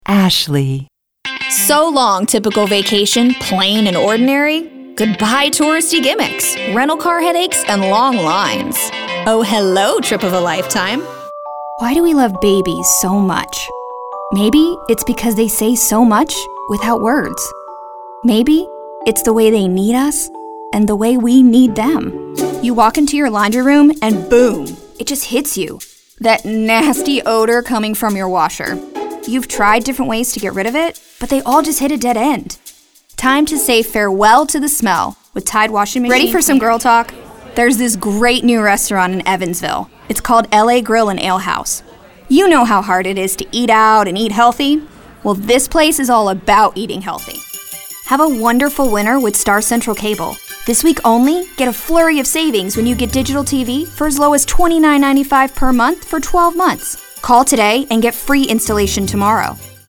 Young and fresh girl-next-door with a friendly and relatable smile
announcer, high-energy, perky, upbeat
announcer, caring, compelling, friendly, sincere, thoughtful, warm
accented, accented English, announcer, character, classy, friendly, smooth, sophisticated, warm